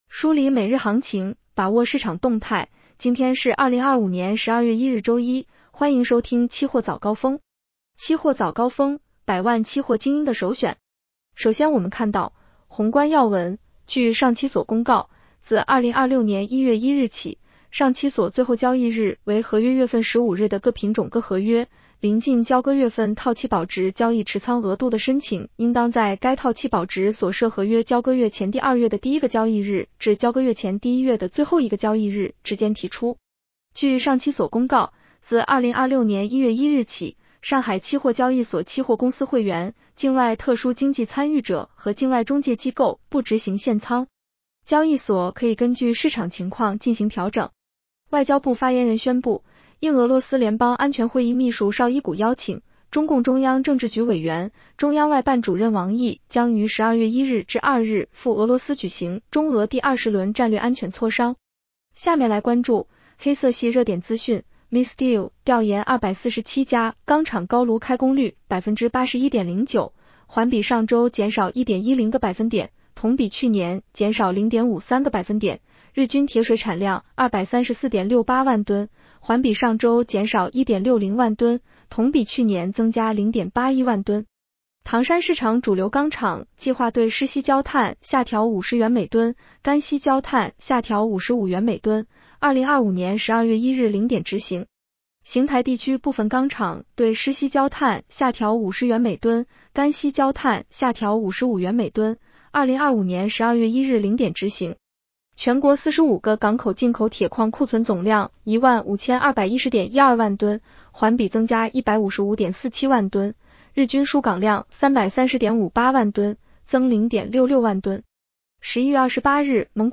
期货早高峰-音频版 女声普通话版 下载mp3 热点导读 1.国家发改委：加强煤炭运行调节，全力以赴做好今冬明春能源保供工作。